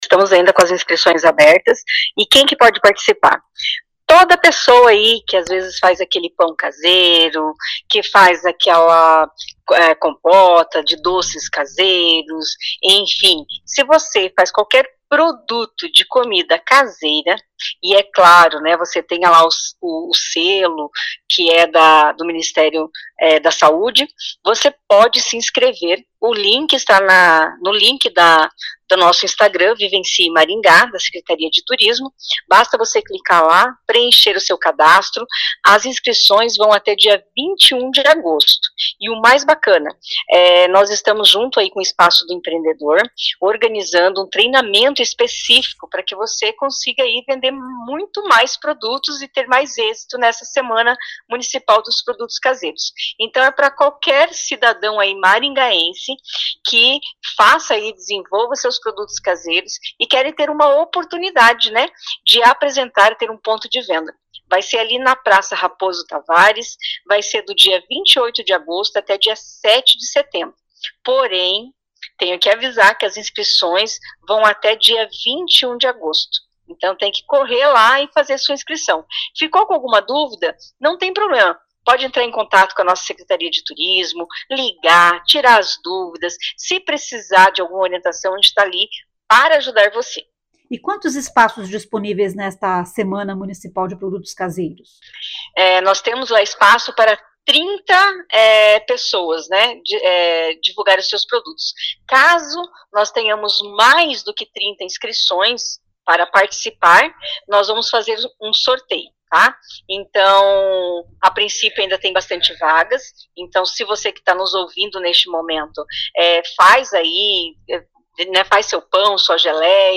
Ouça o que diz a secretária de Aceleração Econômica e Turismo, Isolene  Niedermeyer.